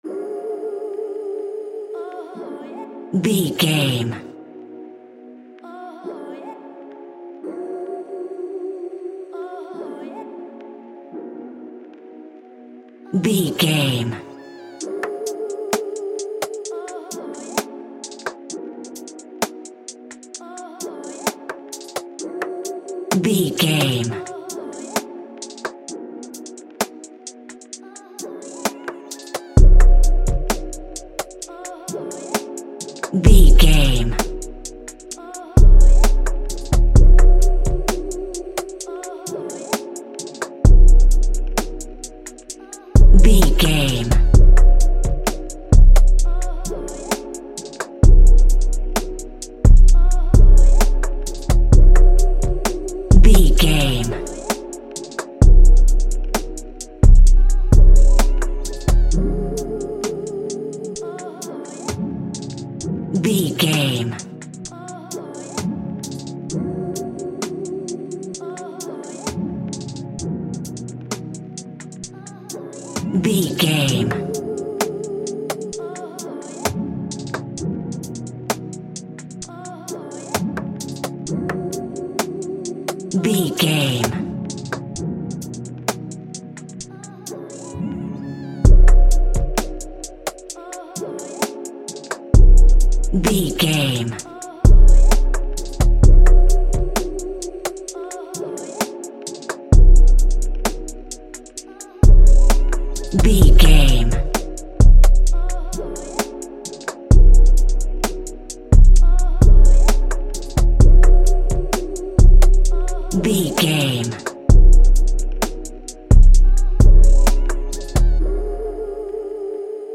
Ionian/Major
drums
calm
mellow